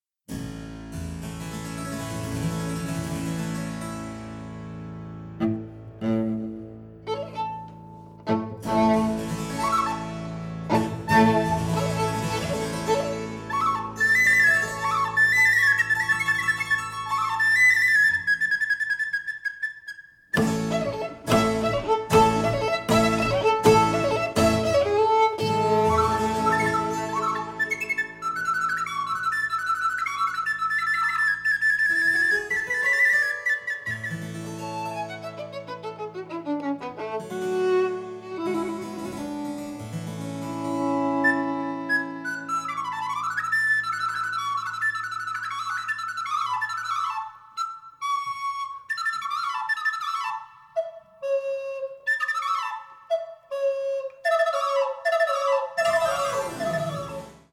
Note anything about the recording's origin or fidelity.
Recorded November 2016 and April 2017 in Loughborough, UK